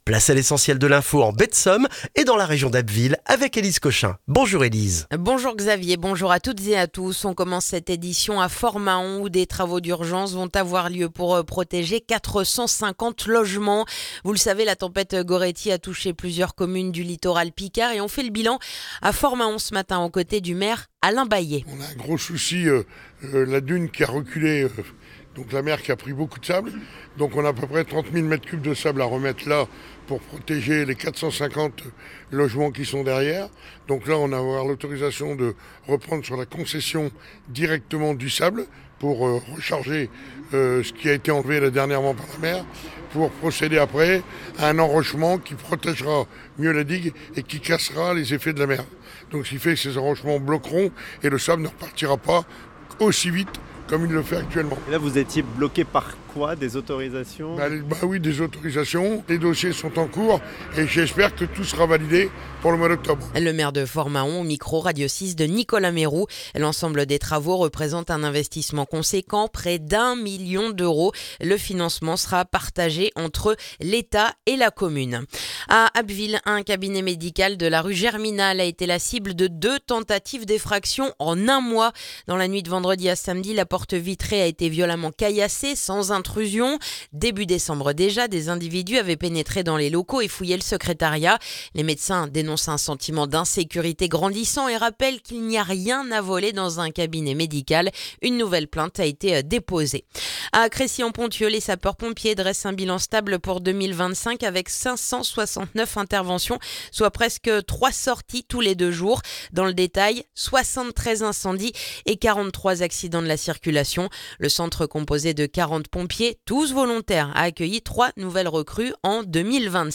Le journal du mardi 20 janvier en Baie de Somme et dans la région d'Abbeville